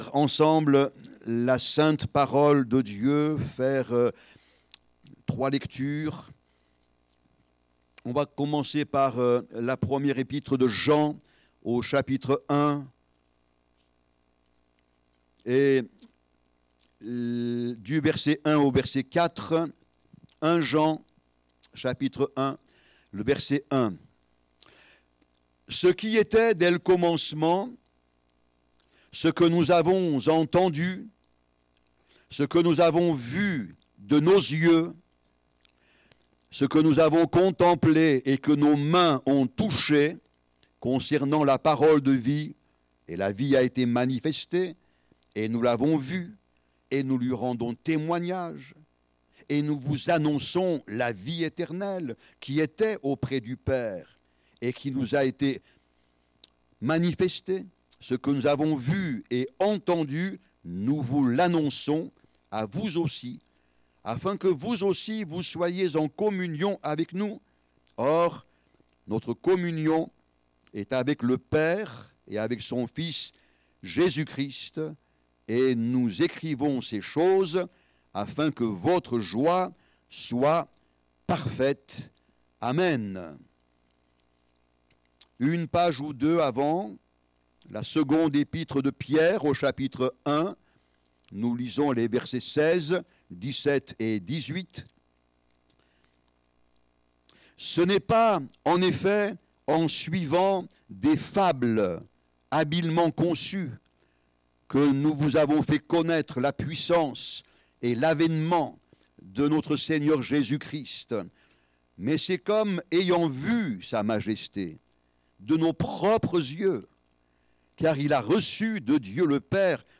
15 avril 2025 Avec Jésus sur la haute montagne Prédicateur